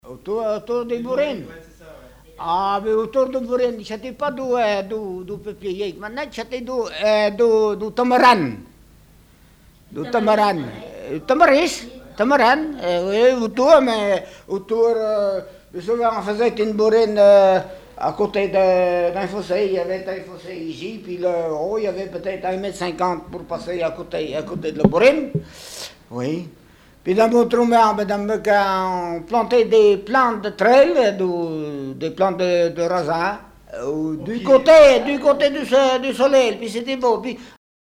Témoignage sur la vie dans une bourrine
Catégorie Témoignage